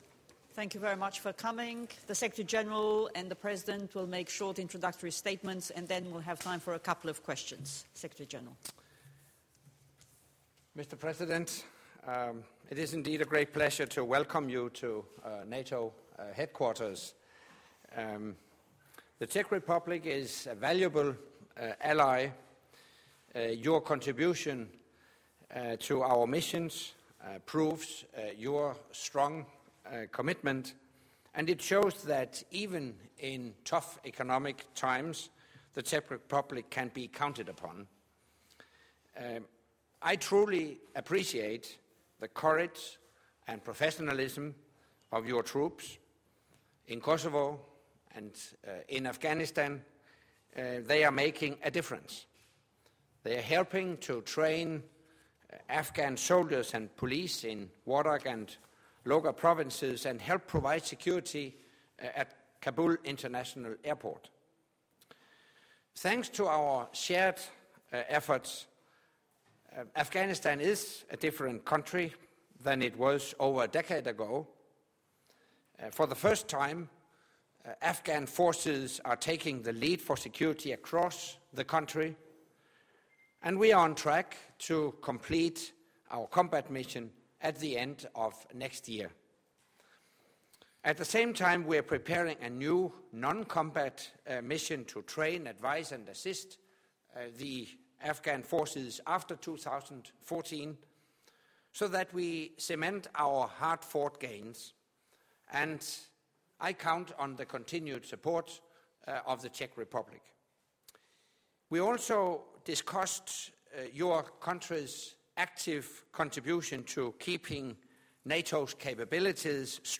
ORIGINAL - Joint Press Point with NATO Secretary General Anders Fogh Rasmussen and the President of the Czech Republic, Mr. Milos Zeman